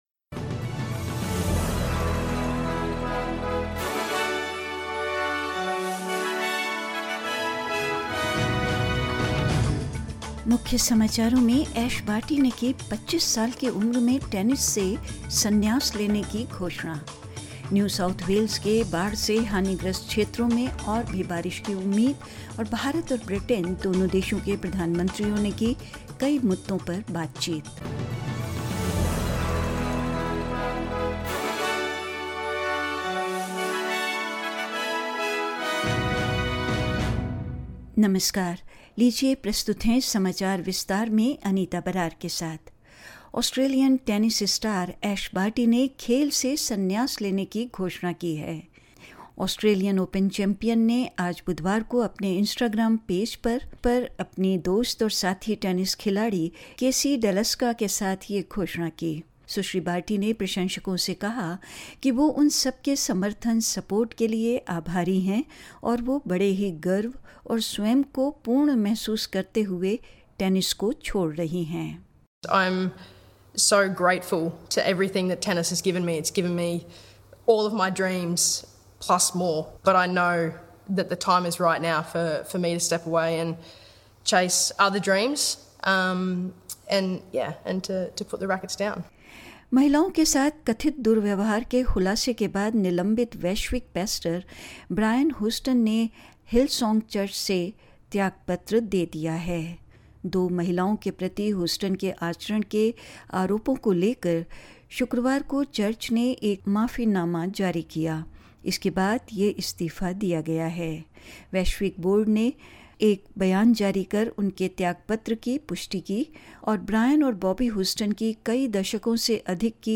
In this latest SBS Hindi bulletin: Ash Barty announces her shock retirement from tennis at the age of 25; Flood-devastated areas of New South Wales told to expect even more rainfall; Indian Prime Minister Narendra Modi spoke with his British counterpart Boris Johnson on the phone to discuss bilateral interests and more news.